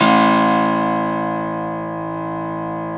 53f-pno01-A-1.wav